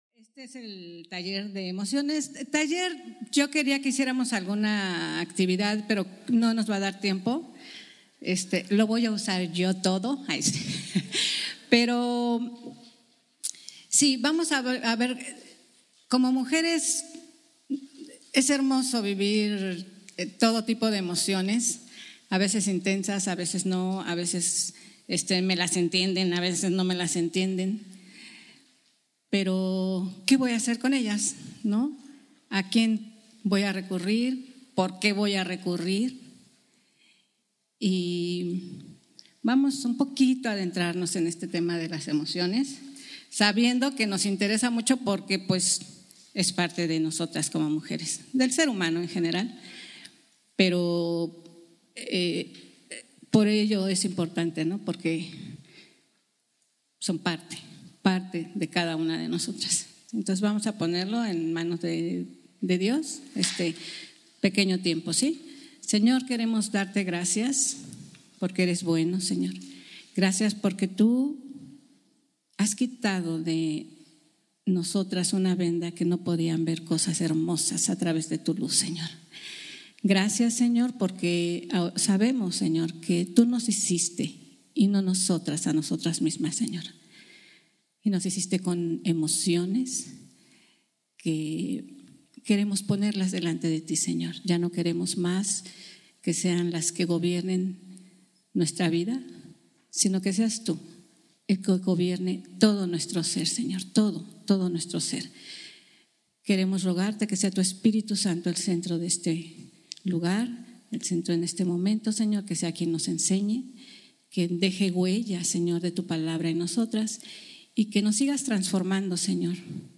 Taller – La Imagen Completa En Mis Emociones, Encontrando La Paz
Retiro 2018